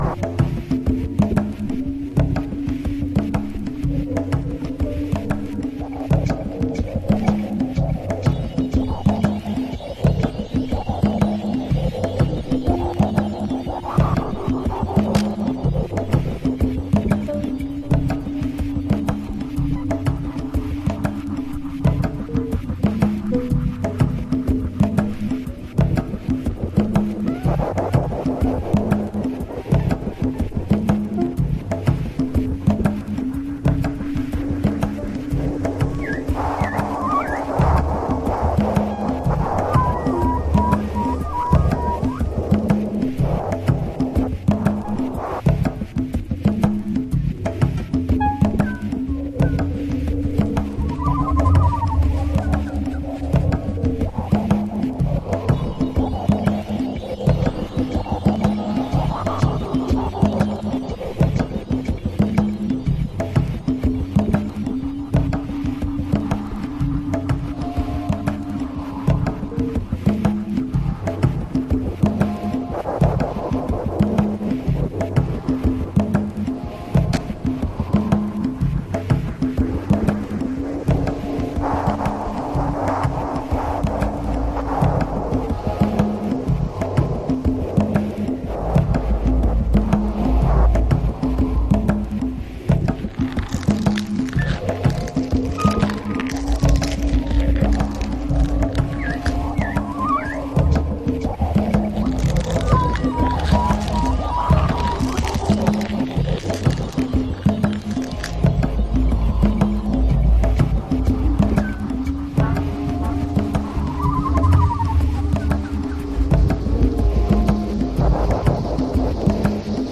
House / Techno
密林シャッフルビートと徐々に捩じれていくレイヤーで時間軸狂わせてきます。